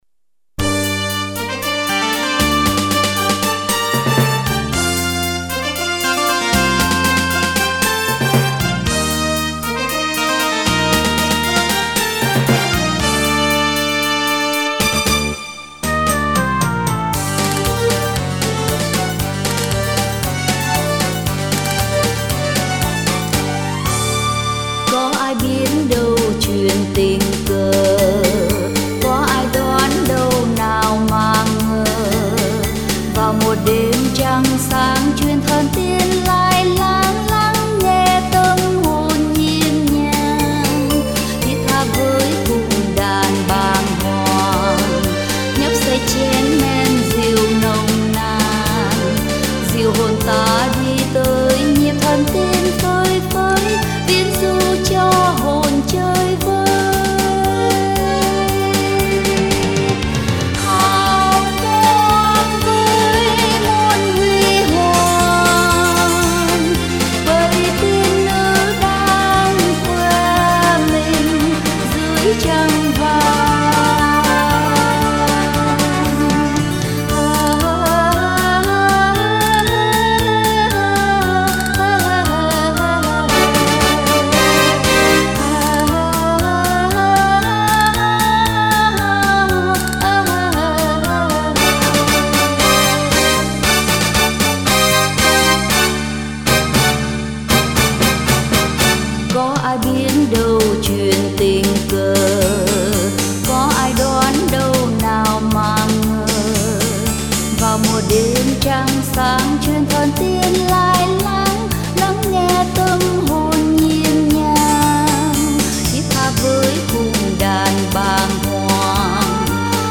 tiếng hát ngọt ngào
..thu tại Austin, Texas...